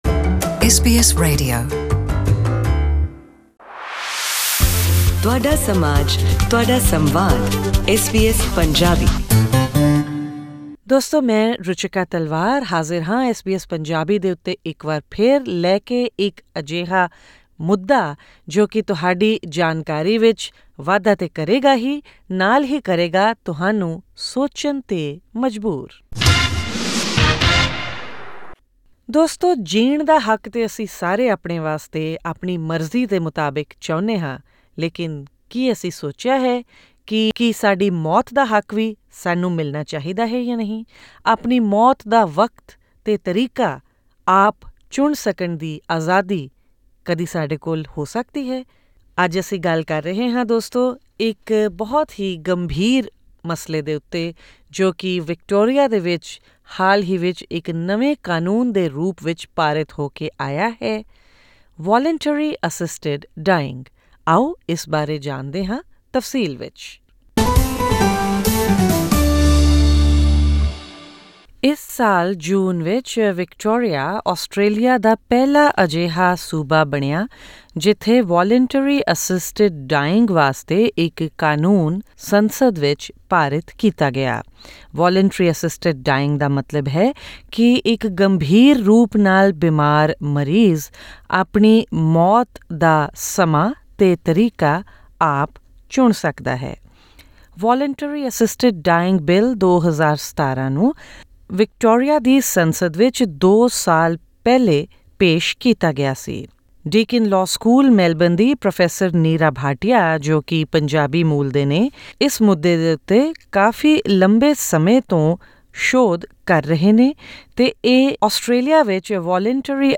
What kind of a medical condition can make a patient eligible for choosing the time and mode of his/her death under Victoria's landmark law? A Punjabi legal expert explains in detail in this interview.